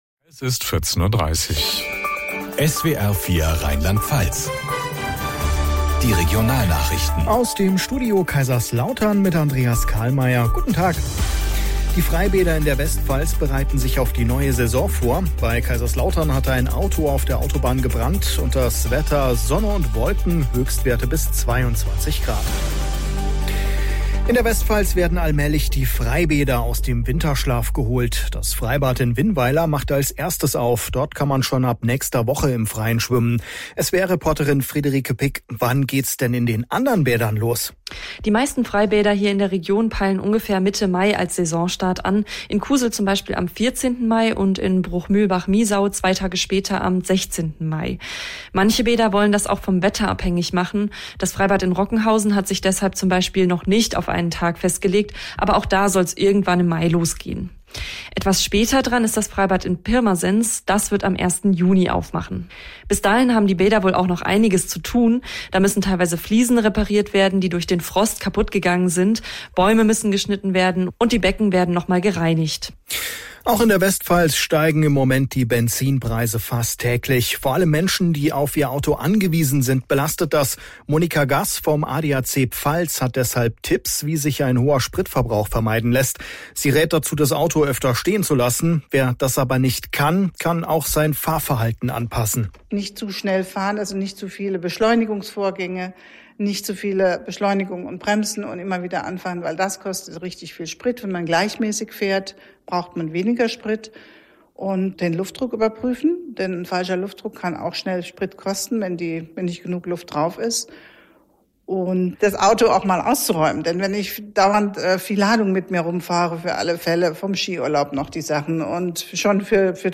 SWR Regionalnachrichten aus Kaiserslautern - 14:30 Uhr